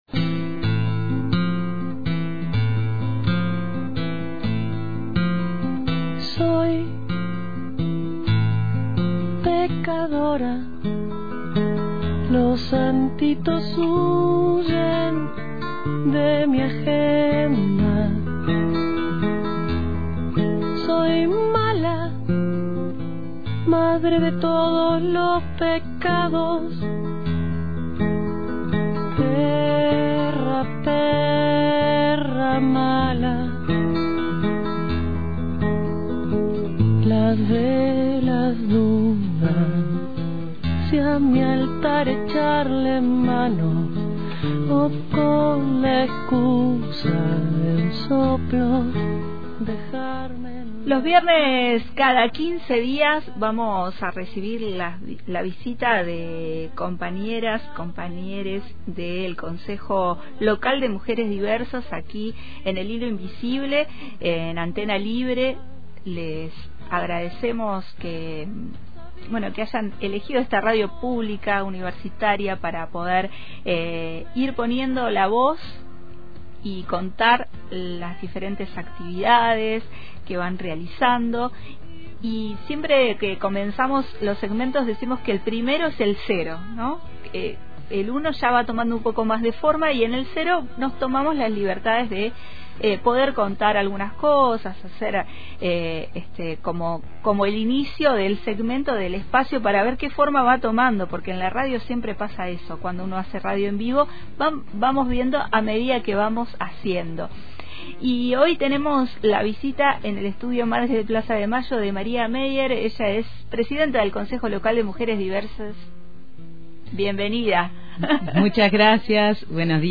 A partir de hoy y cada quince días, estaremos recibiendo a las diferentes integrantes del Concejo Local de Mujeres Diversas en su nuevo micro radial dentro del Hilo Invisible.